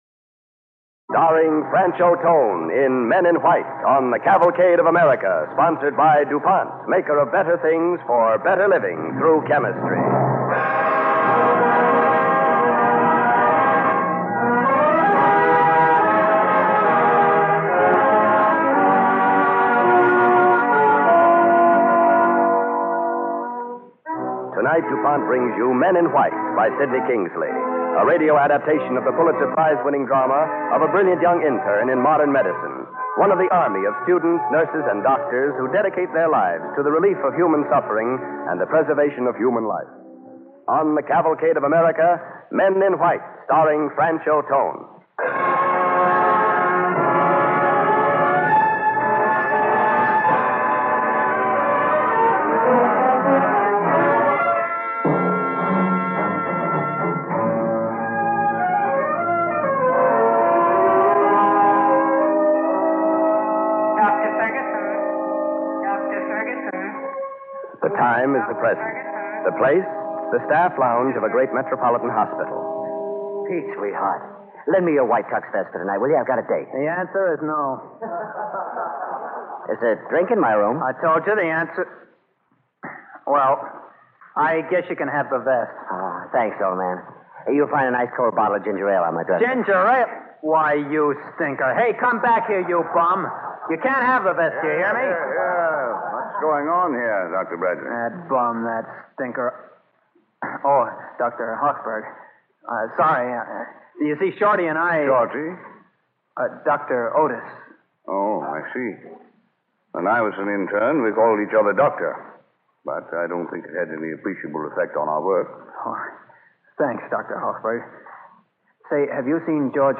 Men in White, starring Franchot Tone
Cavalcade of America Radio Program